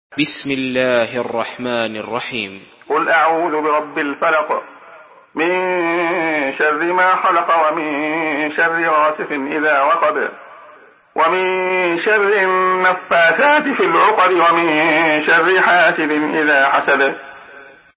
Surah Al-Falaq MP3 in the Voice of Abdullah Khayyat in Hafs Narration
Murattal